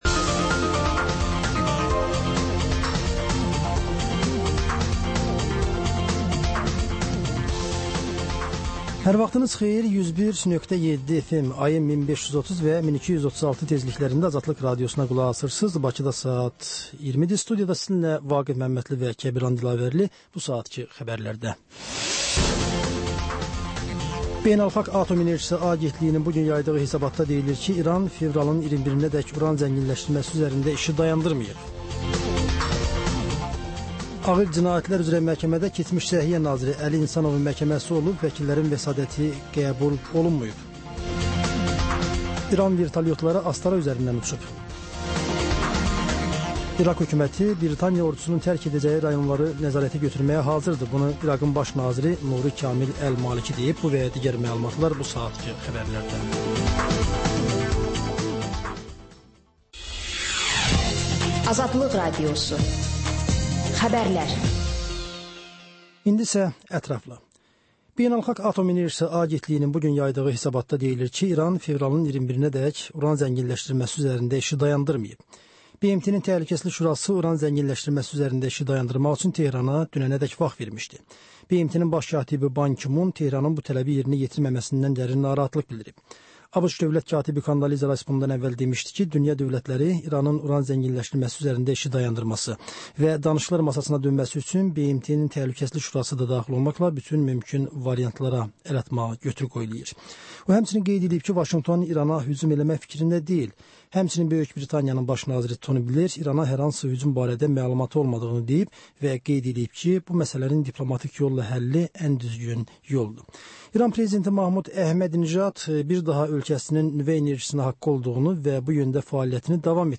Bugün nələr gözlənir, nələr baş verib? Xəbər, reportaj, müsahibə.